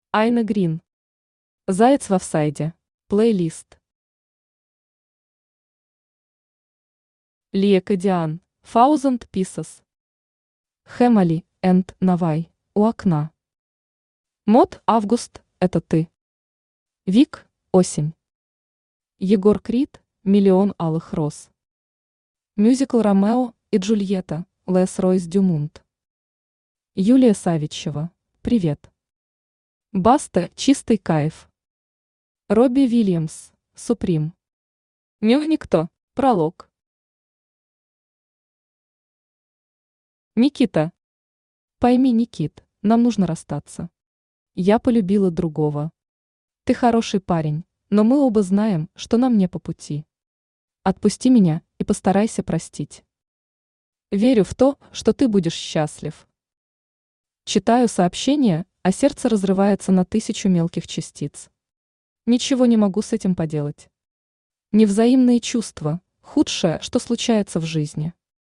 Аудиокнига Заяц в офсайде | Библиотека аудиокниг
Aудиокнига Заяц в офсайде Автор Айна Грин Читает аудиокнигу Авточтец ЛитРес.